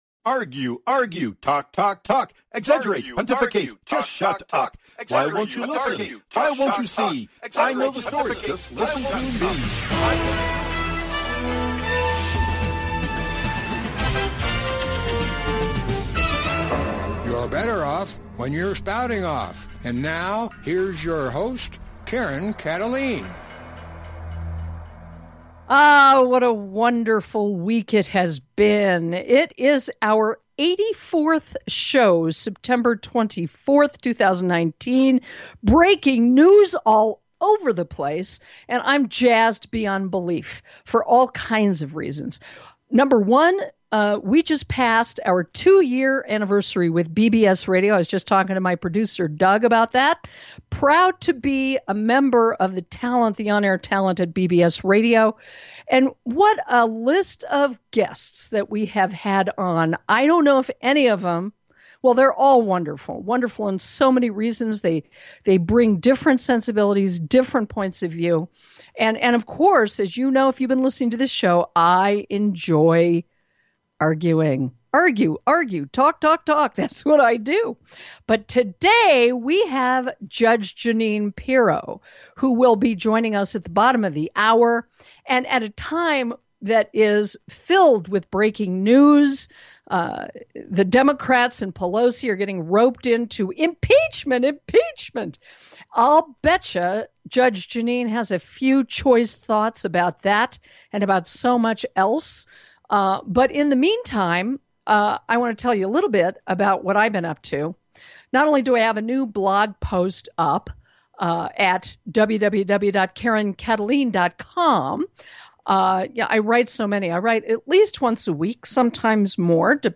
Talk Show Episode, Audio Podcast, Spouting Off and Guest, JUDGE JEANINE PIRO on , show guests , about JUDGE JEANINE PIRO, categorized as News,Politics & Government,Society and Culture
Guest, JUDGE JEANINE PIRO